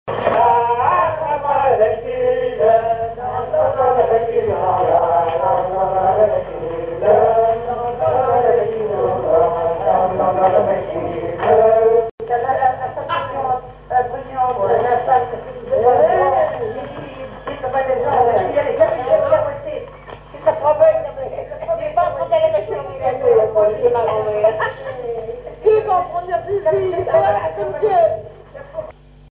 branle
Couplets à danser
collecte en Vendée
chansons traditionnelles et commentaires
Pièce musicale inédite